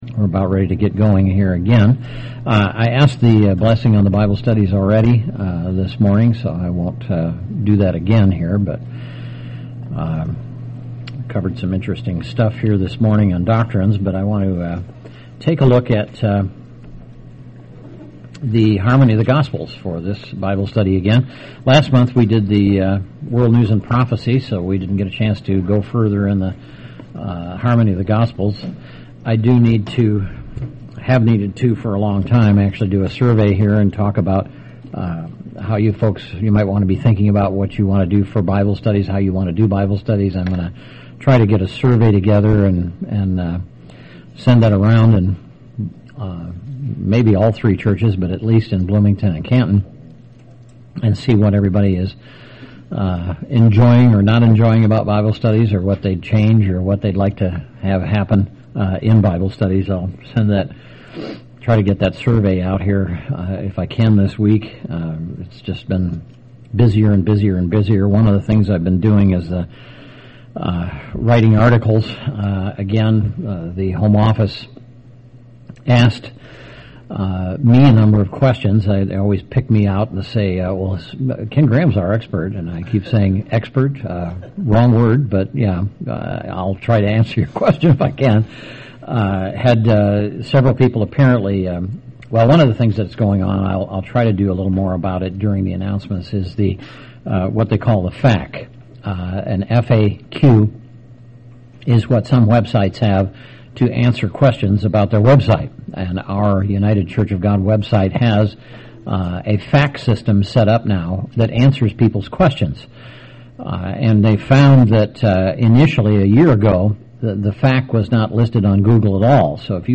A Bible study on Section 32 of the Harmony of the Gospels, beginning in John 3:1 with the story of Nicodemus.